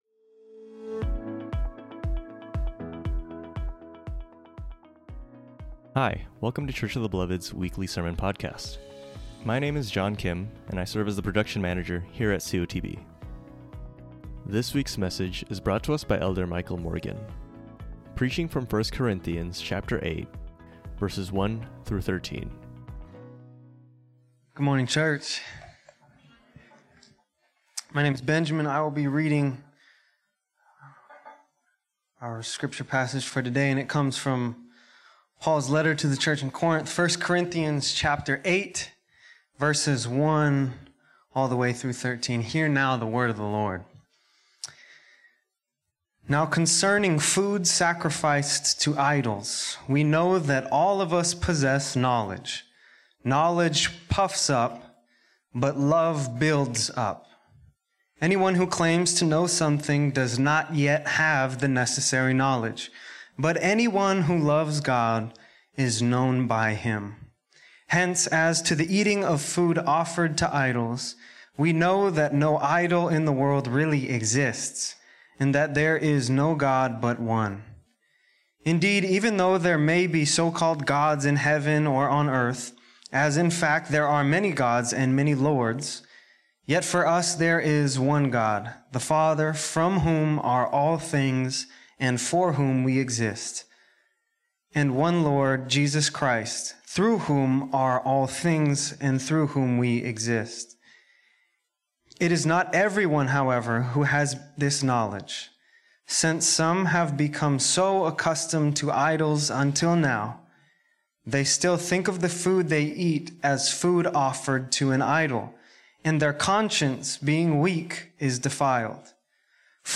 preaches from 1 Corinthians 8:1-13